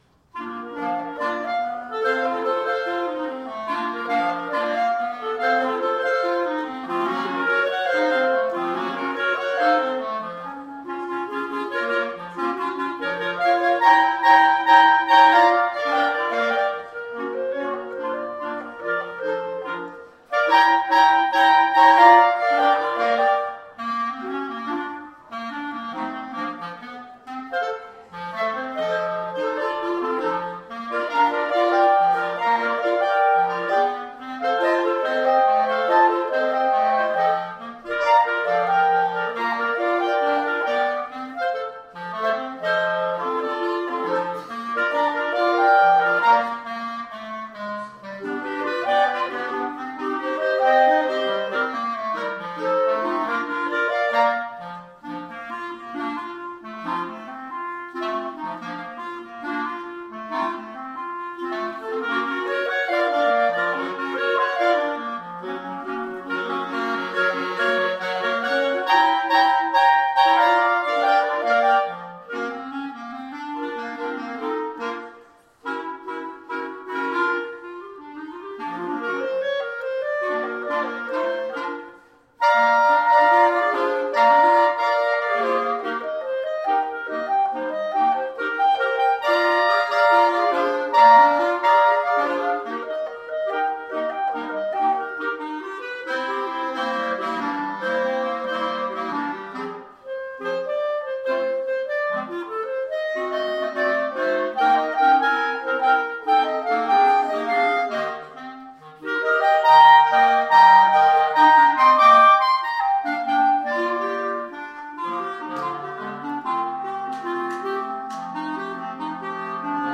They are from the coffee house event we held as a fundraiser at Mount Calvary back in may of 2010, so they’re about 3 years old, but just now seeing the light of day.
clarinet